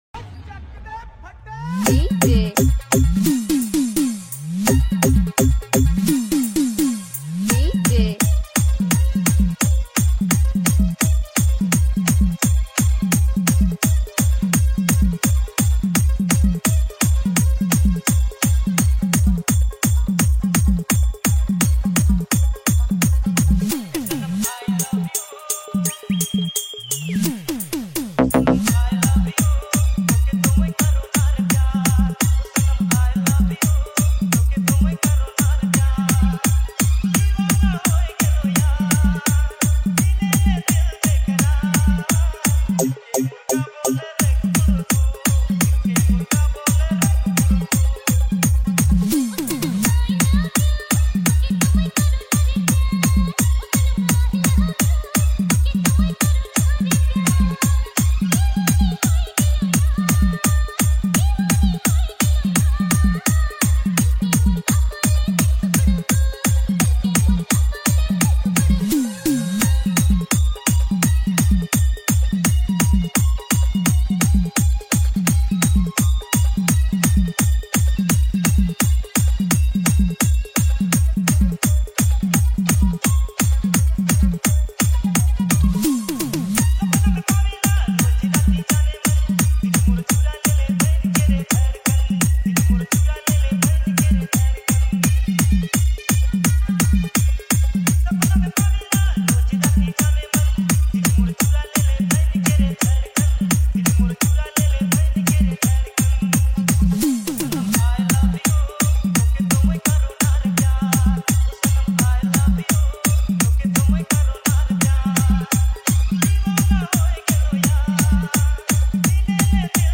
New Nagpuri Dj Song 2025